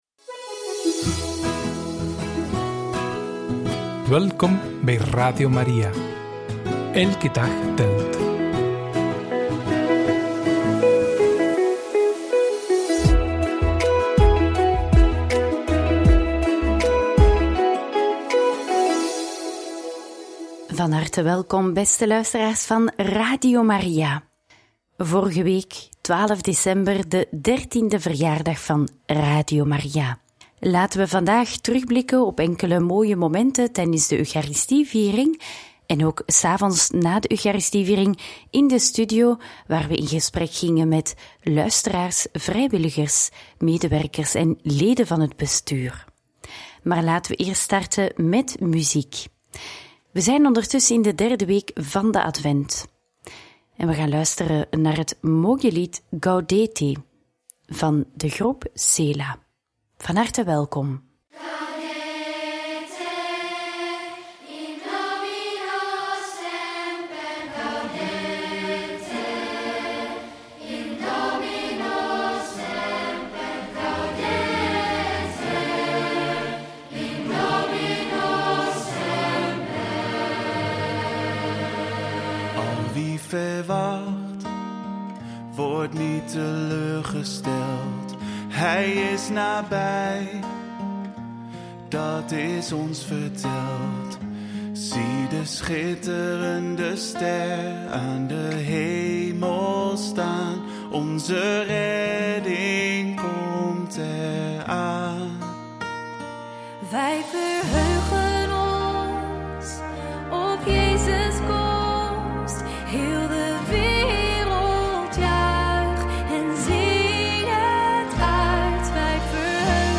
Homilie tijdens de Eucharistieviering voor de verjaardag van Radio Maria